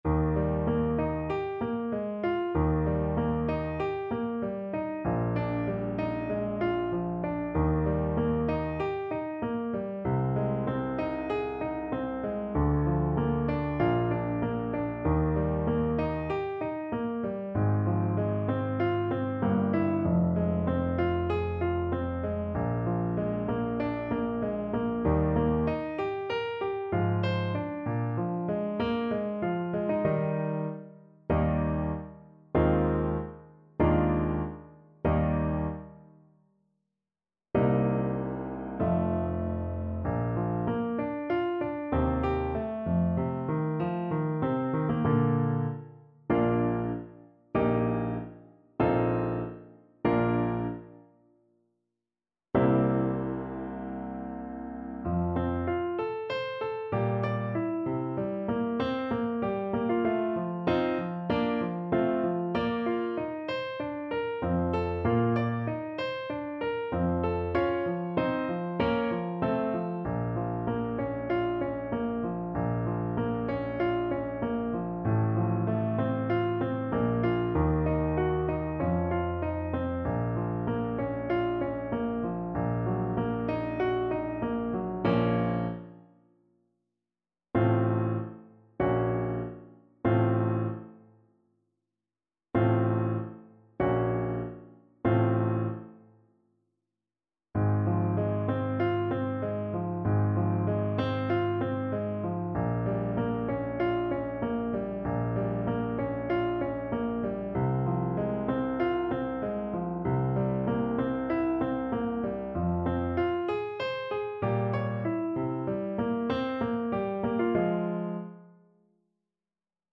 Eb major (Sounding Pitch) F major (Trumpet in Bb) (View more Eb major Music for Trumpet )
4/4 (View more 4/4 Music)
~ = 96 Andante
Classical (View more Classical Trumpet Music)